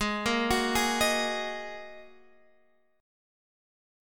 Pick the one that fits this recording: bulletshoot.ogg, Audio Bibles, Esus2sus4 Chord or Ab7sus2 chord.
Ab7sus2 chord